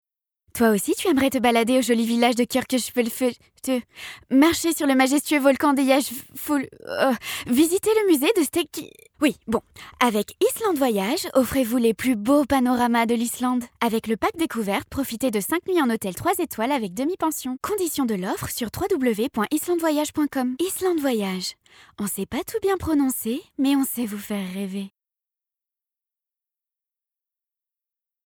Voix off
Pub démo
5 - 32 ans - Soprano